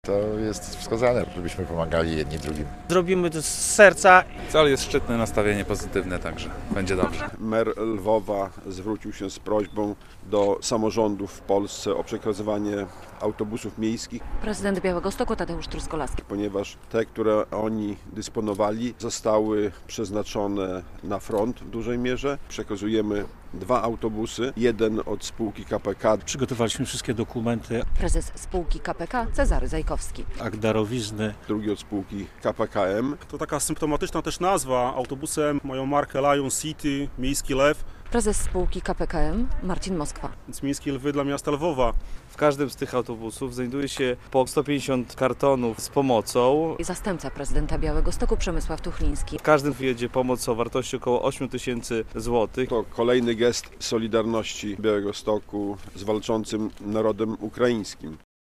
Autobusy dla Lwowa - relacja